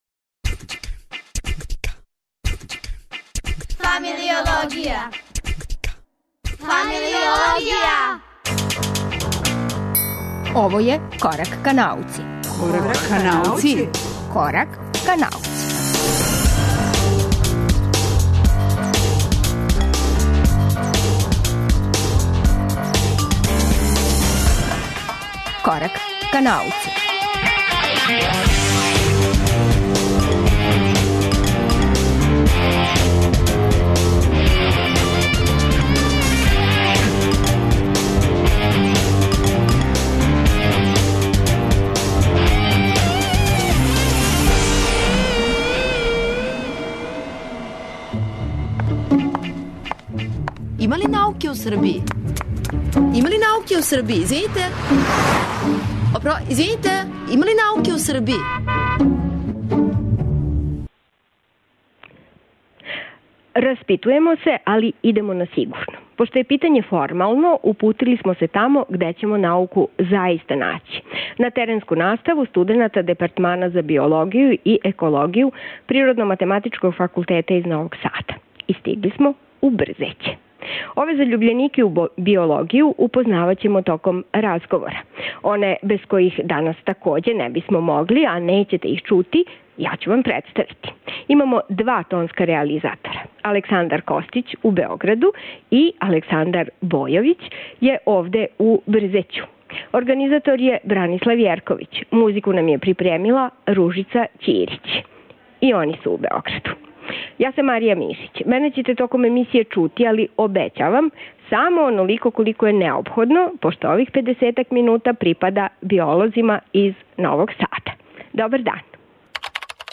Пошто је питање формално, упутили смо се тамо где ћемо науку заиста наћи - на теренску наставу студената Департмана за биологију ПМФ из Новог Сада. И стигли смо у Брзеће.